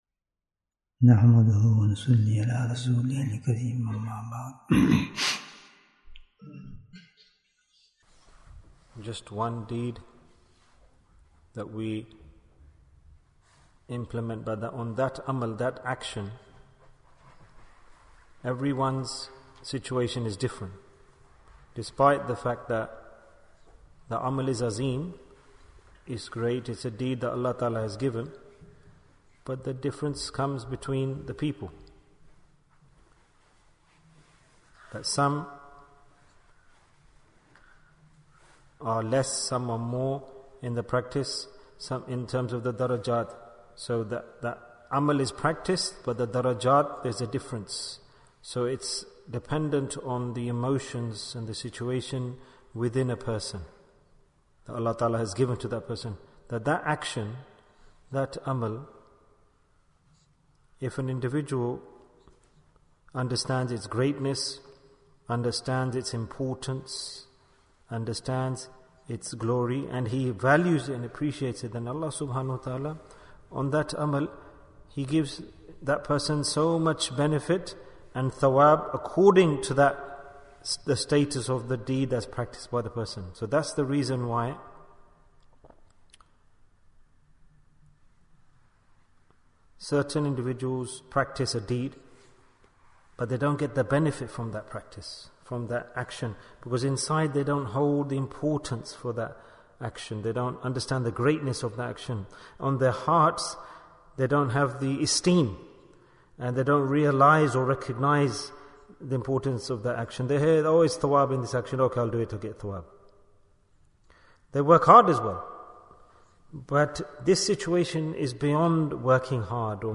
Blessings Depend on the Greatness in Your Heart Bayan, 42 minutes9th April, 2023